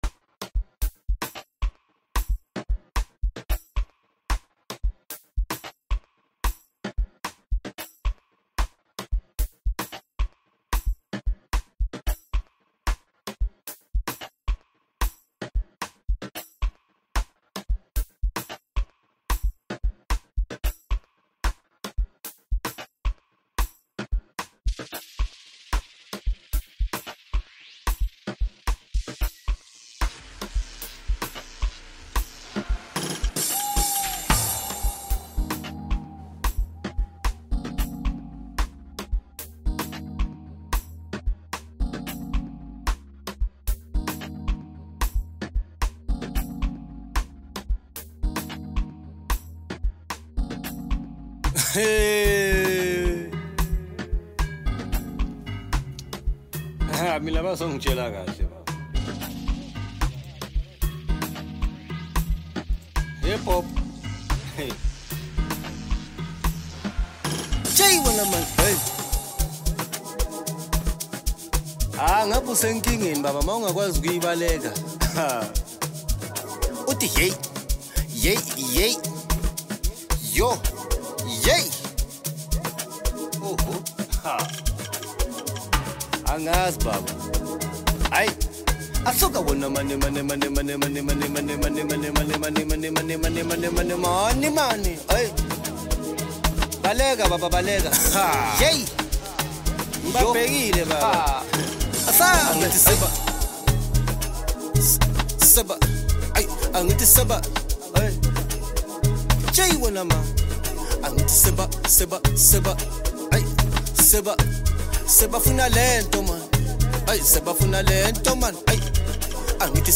Home » Amapiano » Deep House » Hip Hop » Latest Mix